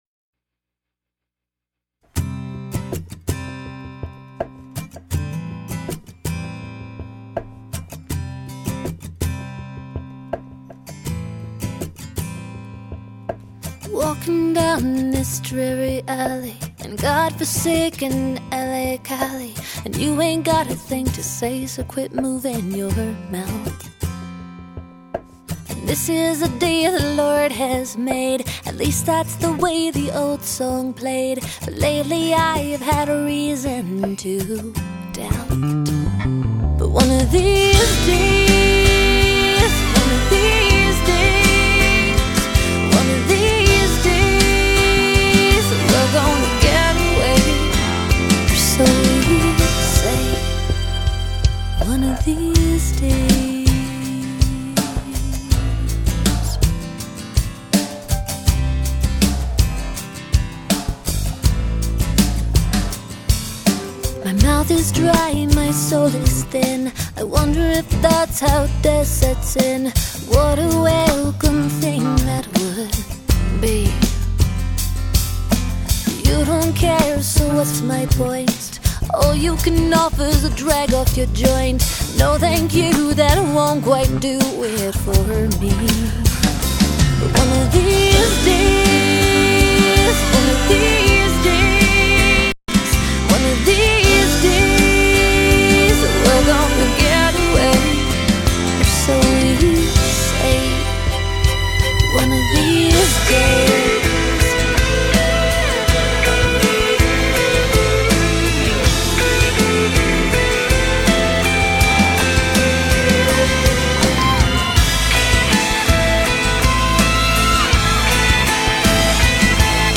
piano and guitar